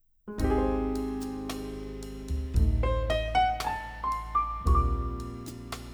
The jazz signal
funky